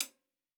TC Live HiHat 12.wav